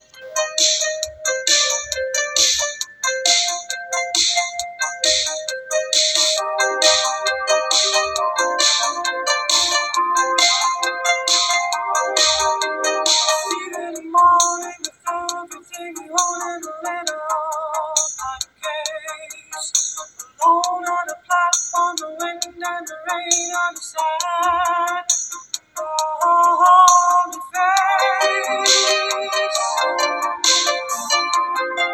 Basically it sounds like over-compressed audio that gets easily beaten with 22.5kHz 64kbps MP3 (comparing to what I listened to recently).
I just recorded music playing from my laptop, so yeah, the audio source isn't that great either, but you can hear the artifacts there.
Both were recorded lossless.